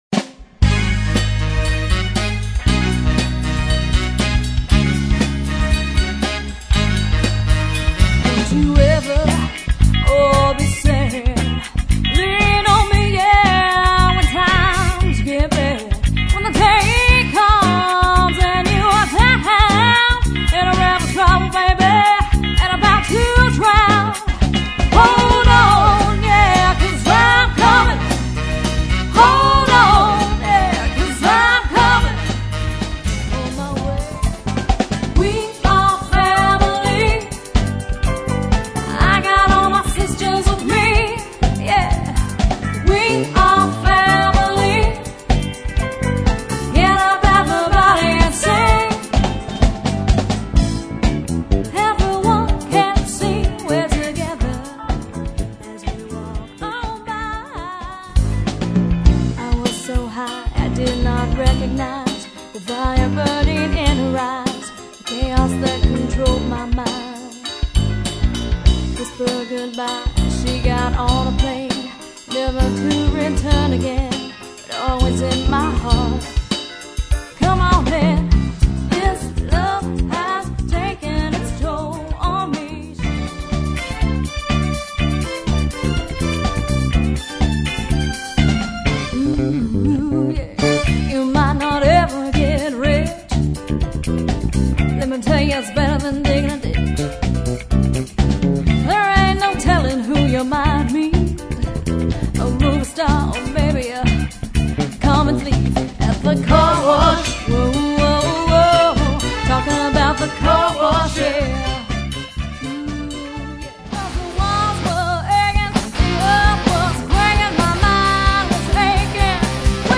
six piece party band